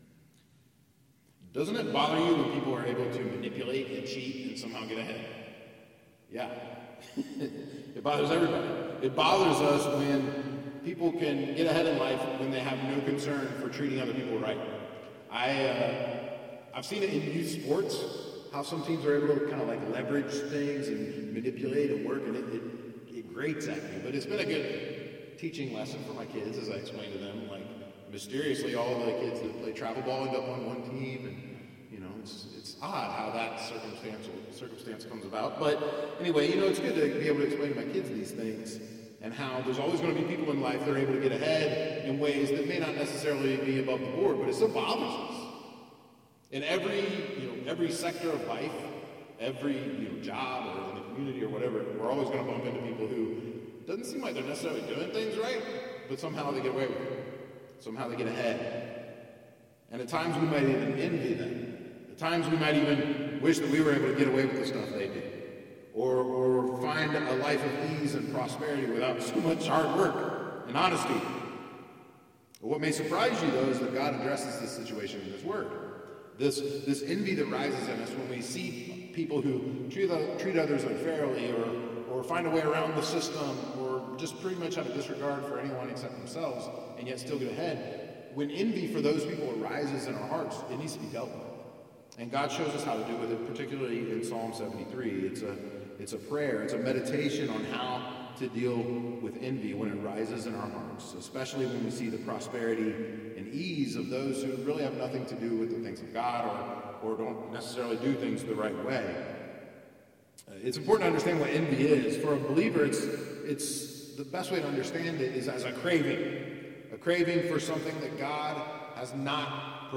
gods-guide-for-lifes-experiences-sermon-3-psalm-73.mp3